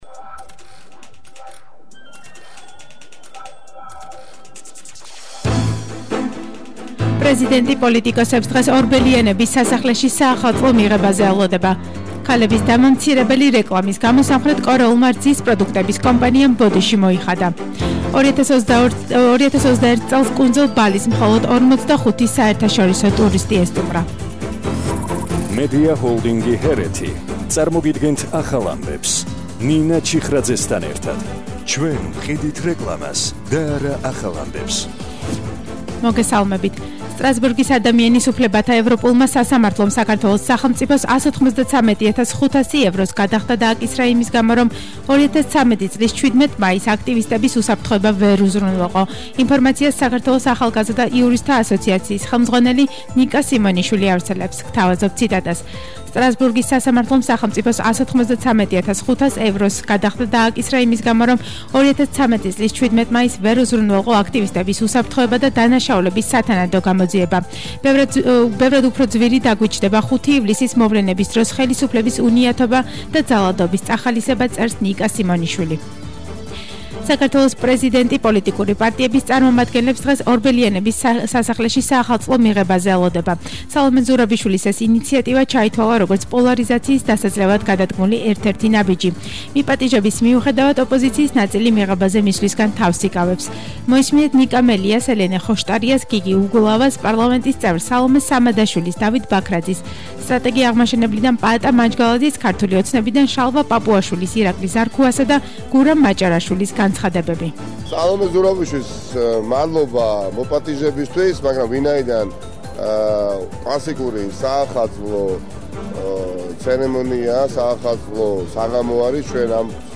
ახალი ამბები 16:00 საათზე – 16/12/21 - HeretiFM